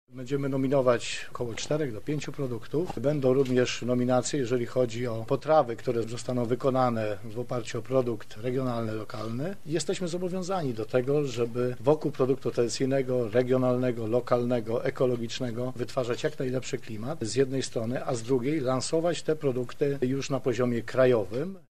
Ryszard Góra– mówi Burmistrz Bełżyc Ryszard Góra.